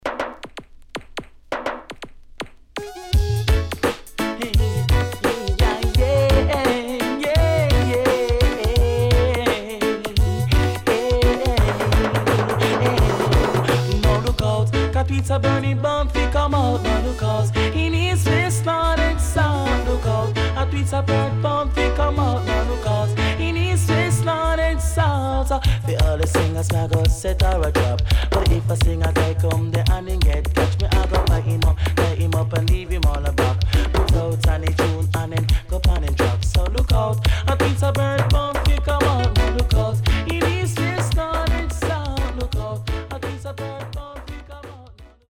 HOME > DISCO45 [DANCEHALL]  >  COMBINATION
SIDE A:うすいこまかい傷ありますがノイズあまり目立ちません。